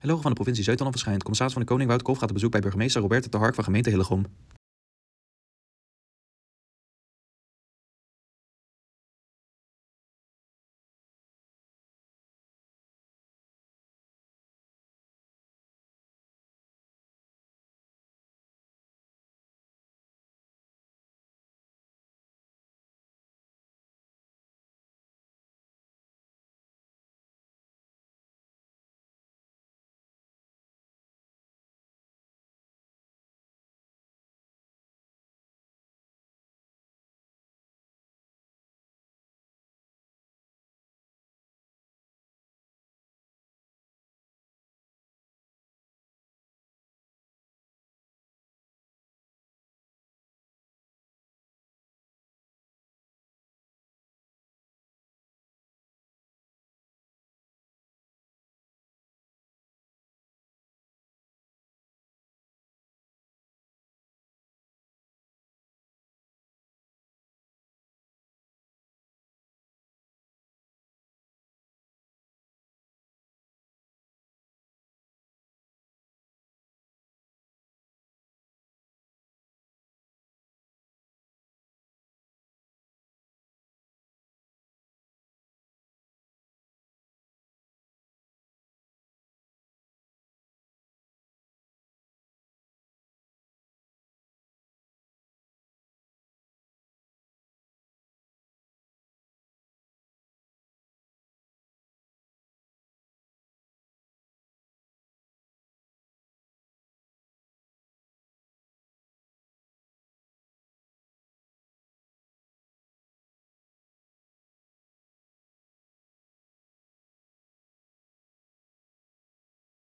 Commissaris van de Koning Wouter Kolff in gesprek met de burgemeester van Hillegom.